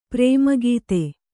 ♪ prēma gīte